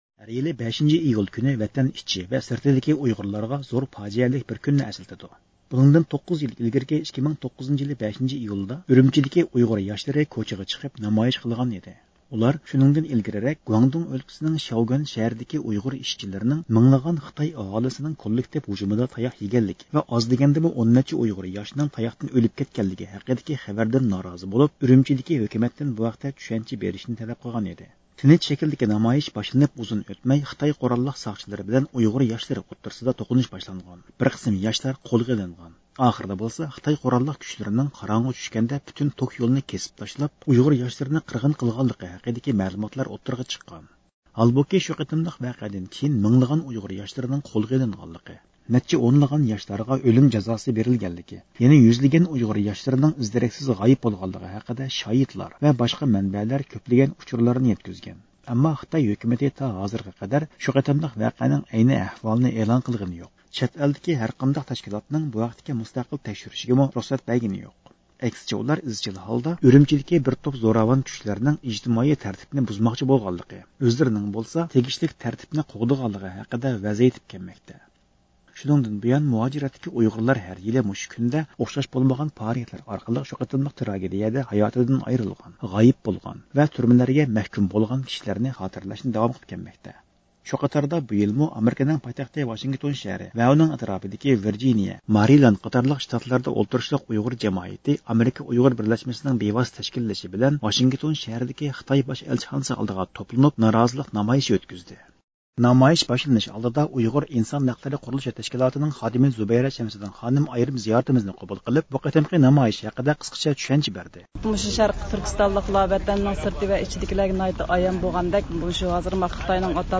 5-ئىيۇل كۈنى چۈشتىن كېيىن سائەت ئىككىدە باشلانغان نامايىشتا ئۇيغۇر جامائىتى ھەر يىلقىدەك ئالدى بىلەن «شەرقىي تۈركىستان مارشى» غا ھۆرمەت بىلدۈردى.
شۇنىڭدىن كېيىن دۇنيا ئۇيغۇر قۇرۇلتىيىنىڭ ئالىي رەھبىرى رابىيە قادىر خانىم سۆز قىلىپ، خىتاي ھاكىمىيىتىنىڭ «5-ئىيۇل ۋەقەسى» دىن بۇيان باشلانغان ئۇيغۇرلارنى باستۇرۇش سىياسىتىنىڭ ئومۇمى ئەھۋالى ھەققىدە چۈشەنچە بەردى.
نۇتۇقلاردىن كېيىن نامايىشقا كەلگەن ئۇيغۇرلار خىتاي ھۆكۈمىتىگە قارشى غەزەپلىك شوئار سادالىرى بىلەن ئەلچىخانا بوشلۇقىنى لەرزىگە كەلتۈردى.